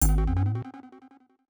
Game Warning Musical Effect.wav